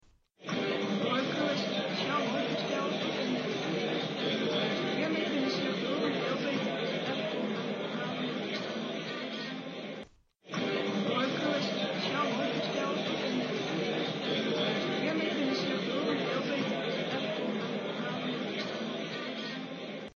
In dit fragment kunt u luisteren naar wat een slechthorende met een modern richtinggevoelig hoortoestel op een feestje hoort.
dit-hoort-een-slechthorende-met-een-modern-digitaal-hoortoestel-op-een-feestje.mp3